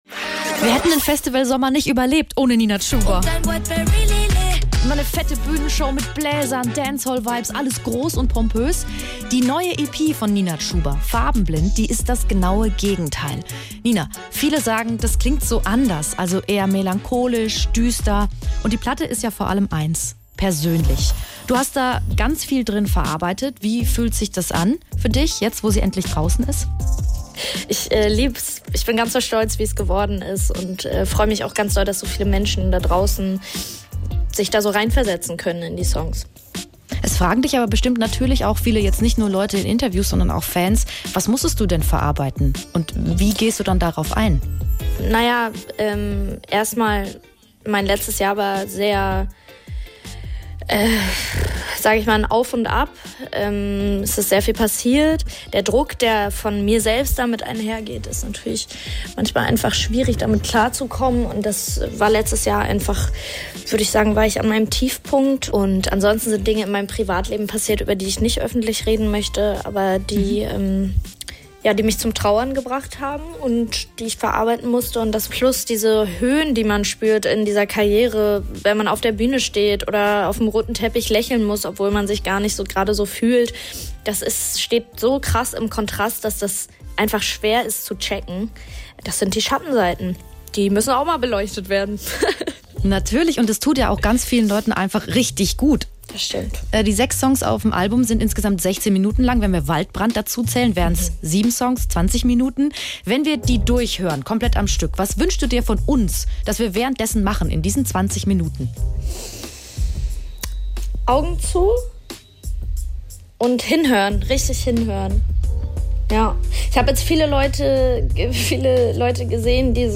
Nina Chuba im SWR3 Interview